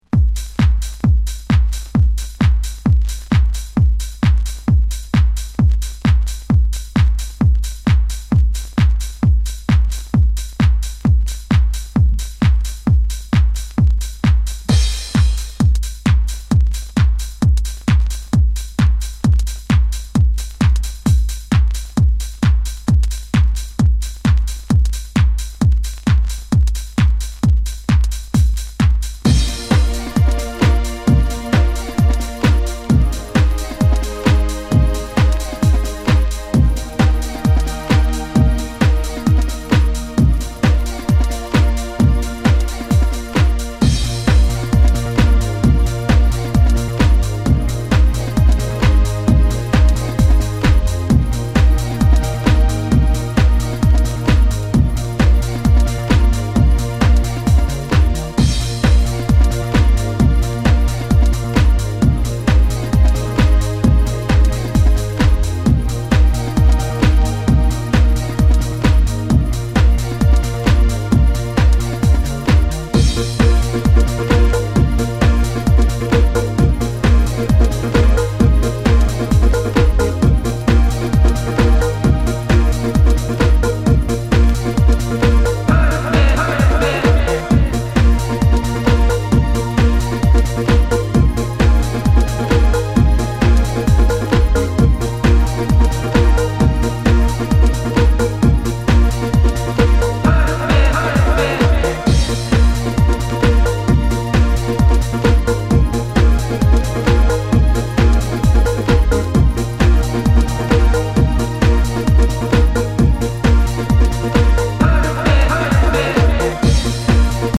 もう鮮やかとしか言いようのない星空が見えるような多幸感チューン！